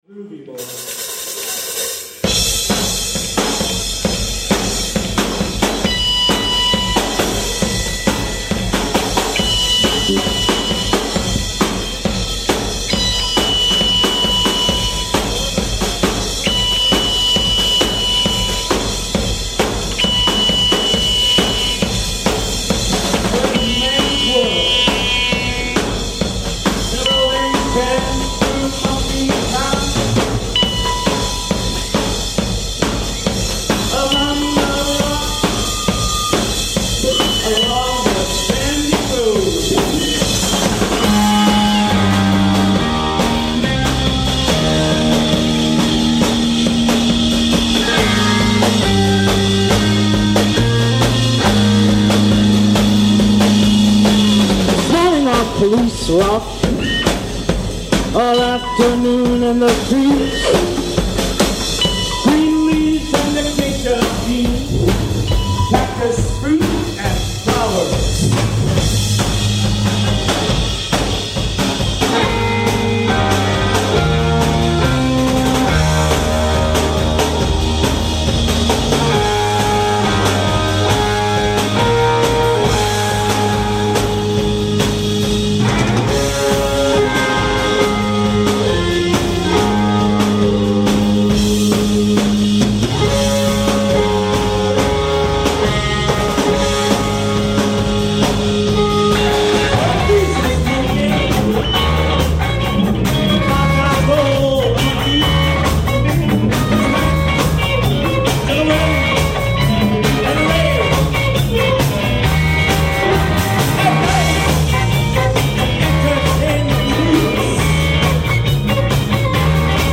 Recorded at Beachland Ballroom Apr. 20, 2006.